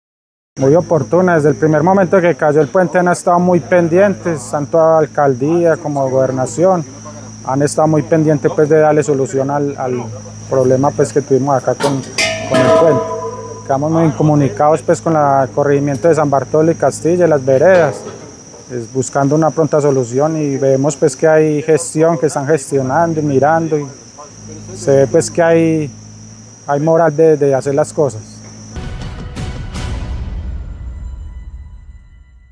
habitante de la zona.